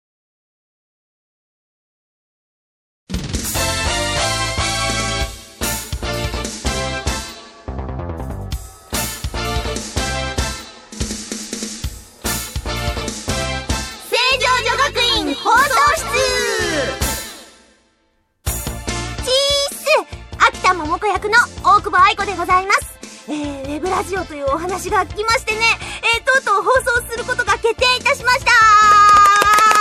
ぐだぐだな様子がちゃんと聞けるかな～？？？
この頃既に風邪引き始めで声変だし...。